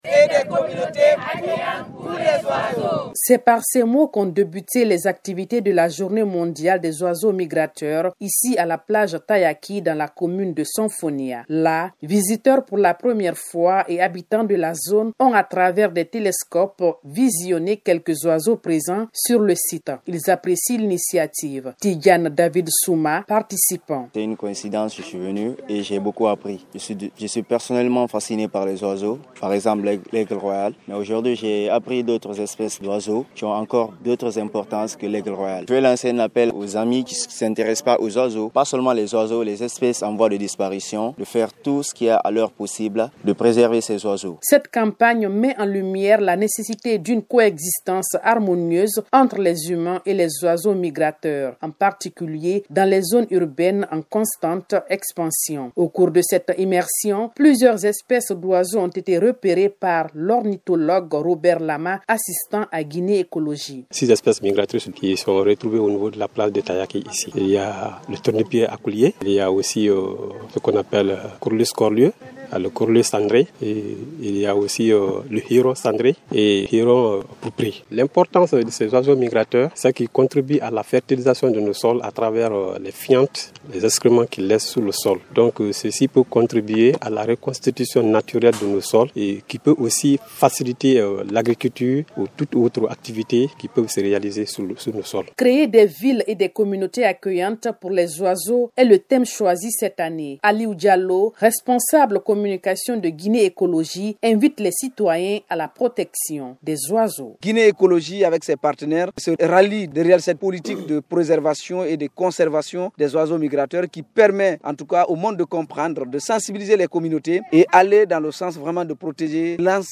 .A cette occasion, l’ONG Guinée écologie et ces partenaires ont organisé à la plage tayaki de conakry une rencontre d’échange avec le riverains et les visiteurs.Objectif, sensibiliser sur la nécessité de préserver les oiseaux migrateurs et leurs habitats par le biais d’actions locales et d’une coopération internationale.
REPORTAGE-JMOISEAUX-MIGRATEURS.mp3